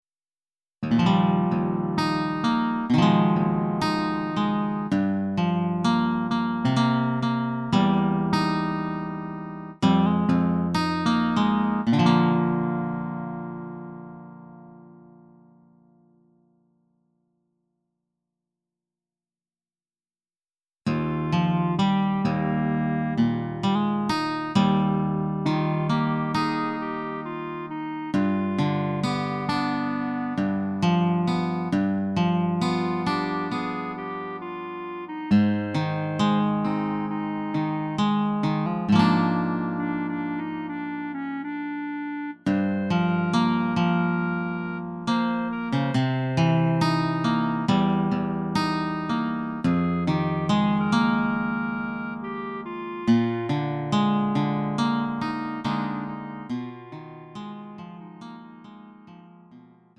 음정 -1키 2:48
장르 가요 구분 Lite MR
Lite MR은 저렴한 가격에 간단한 연습이나 취미용으로 활용할 수 있는 가벼운 반주입니다.